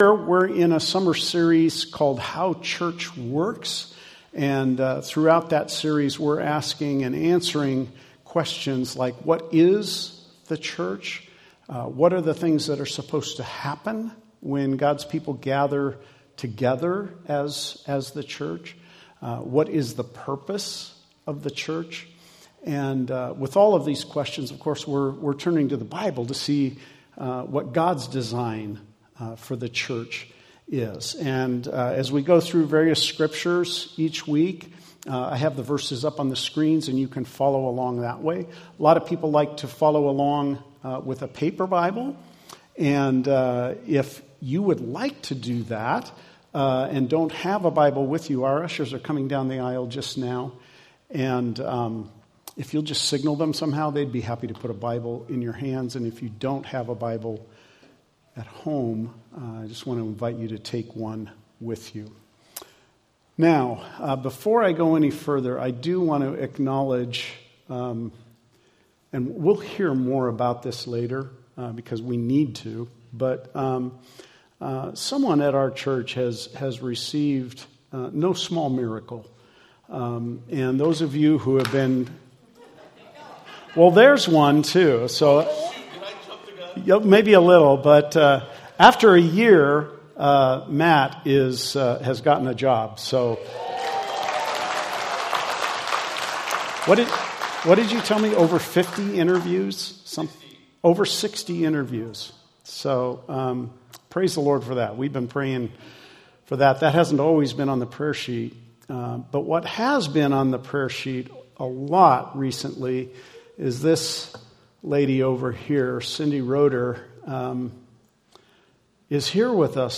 Sunday Messages from Grace Baptist Church in Warren, Oregon. Join us each week for relevant, biblically centered teaching to help you grow as a follower of Jesus.